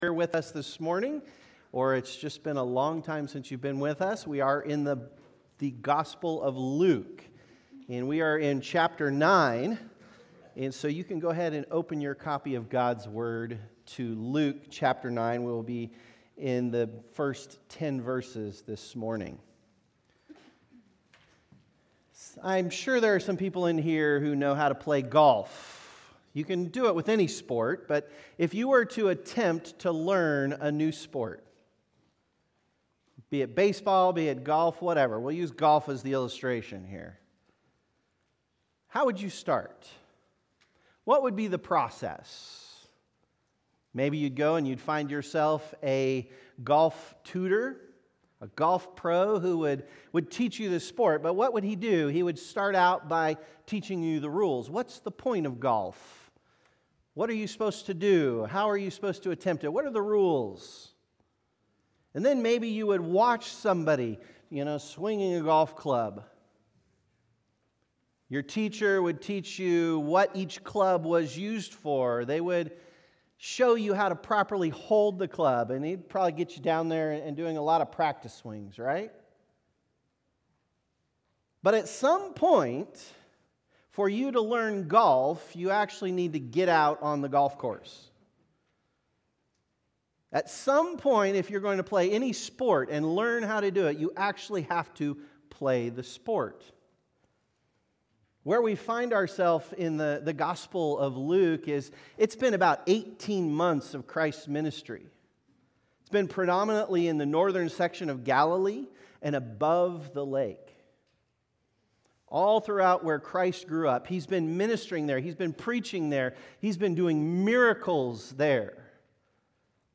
The Savior of the World Current Sermon